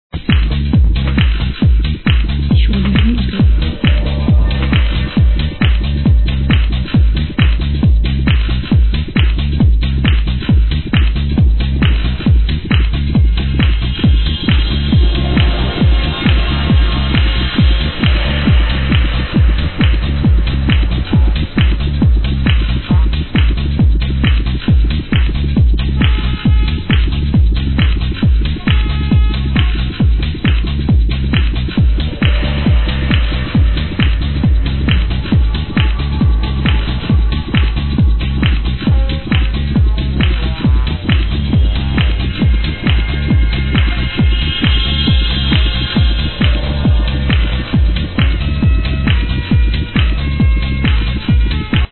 With the saxophone in it